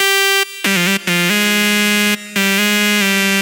140 Bpm的阿拉伯rap铅字，G调
描述：跟着我阿拉伯语, 陷阱, 节日, 陷阱,肮脏, 领先。
Tag: 140 bpm Trap Loops Synth Loops 590.80 KB wav Key : G FL Studio